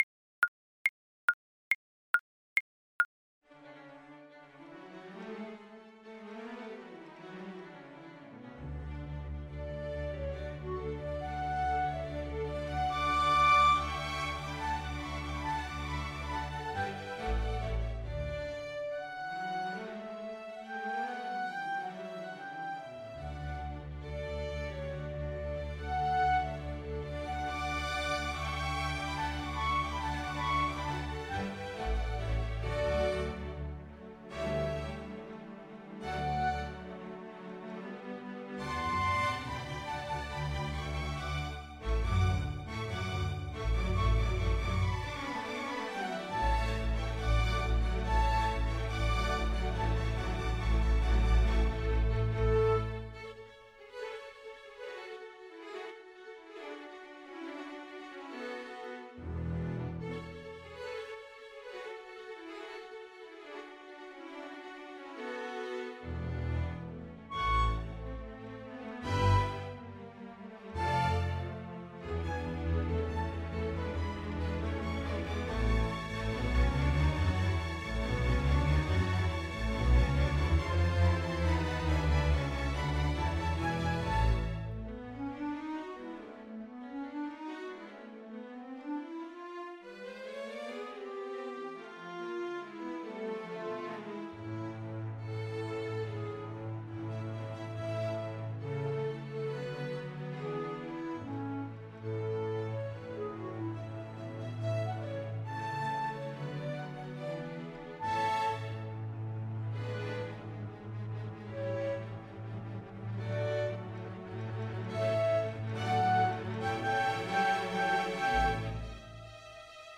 Play (or use space bar on your keyboard) Pause Music Playalong - Piano Accompaniment transpose reset tempo print settings full screen
Violin
2/2 (View more 2/2 Music)
D major (Sounding Pitch) (View more D major Music for Violin )
Classical (View more Classical Violin Music)
figaro_overture_VLN_kar2.mp3